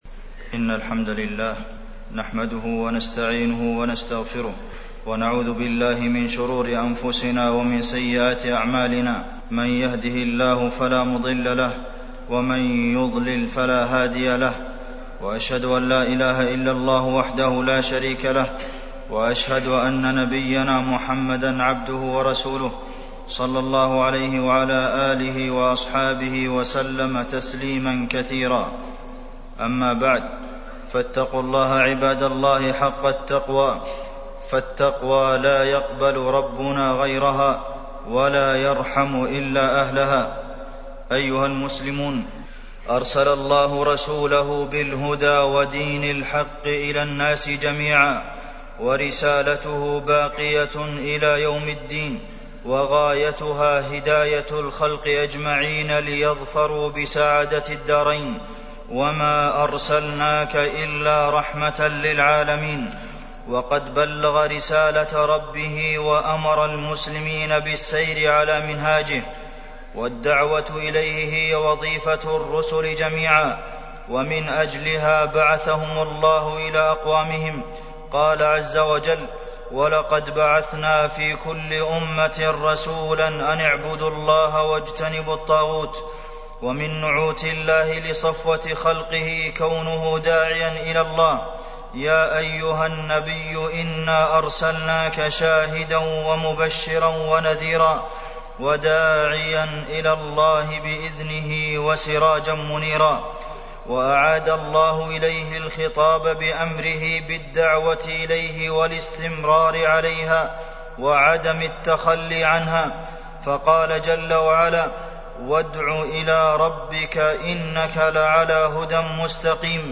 تاريخ النشر ١١ ربيع الأول ١٤٢٨ هـ المكان: المسجد النبوي الشيخ: فضيلة الشيخ د. عبدالمحسن بن محمد القاسم فضيلة الشيخ د. عبدالمحسن بن محمد القاسم الدعوة إلى الله